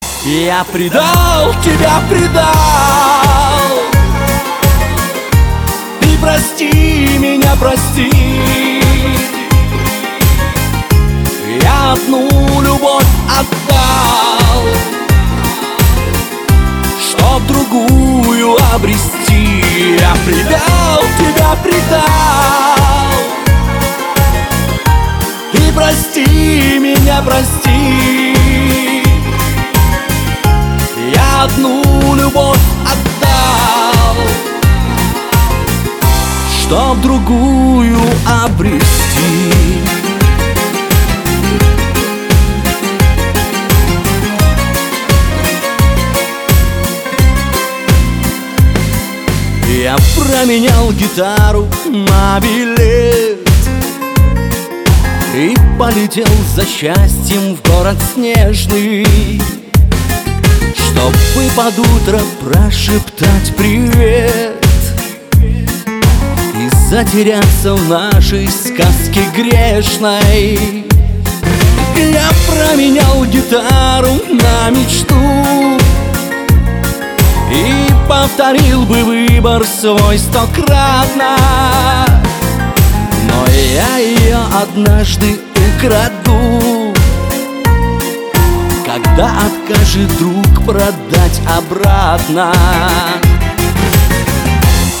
• Качество: 320, Stereo
гитара